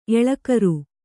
♪ eḷakaru